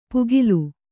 ♪ pugilu